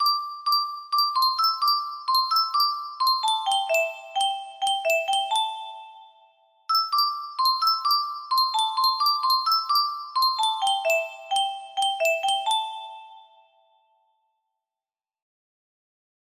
Keepsake music box melody